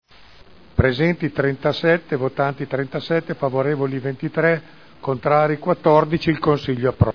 Seduta del 24/01/2011. Mette ai voti l'immediata esecutività su delibera su approvazione modifiche allo Statuto di Hera S.p.A. (Commissione consiliare del 13 gennaio 2011)